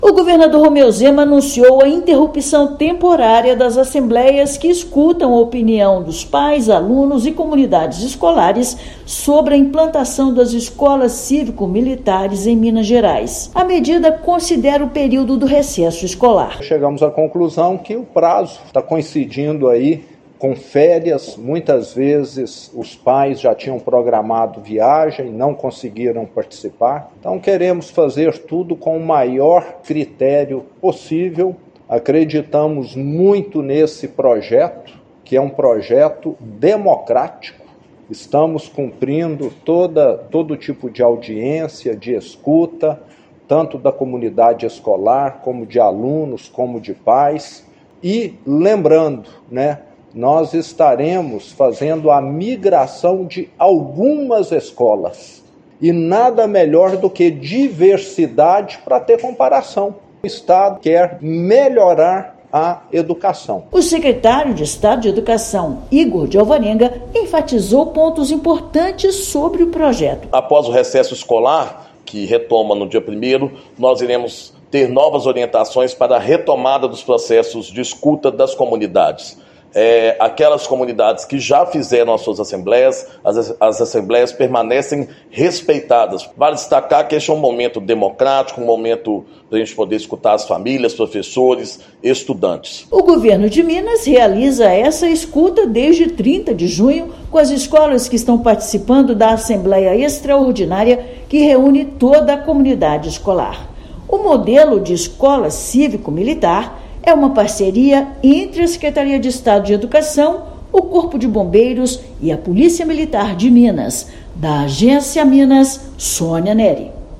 Devido ao período de férias escolares, a ação que promove diálogo com pais, alunos e comunidade escolar foi momentaneamente interrompida. Ouça matéria de rádio.